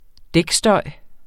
Udtale [ ˈdεg- ]